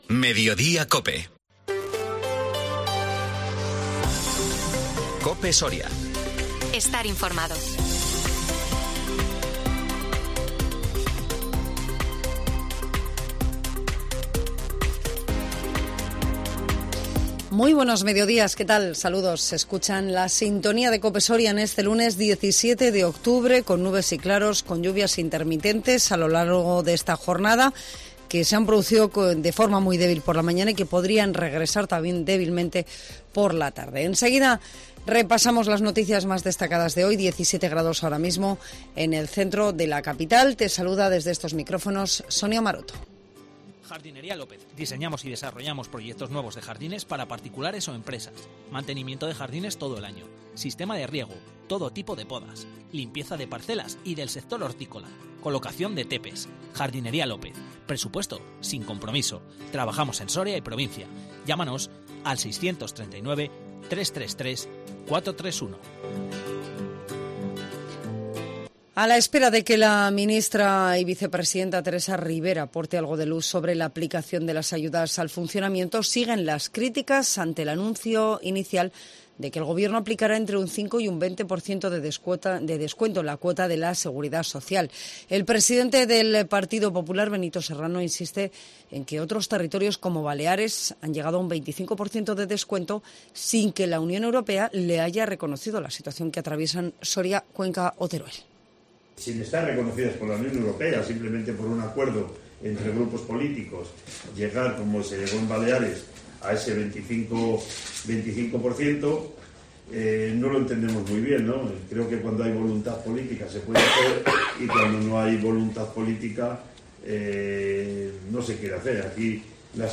INFORMATIVO MEDIODÍA COPE SORIA 17 OCTUBRE 2022